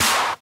clap01.ogg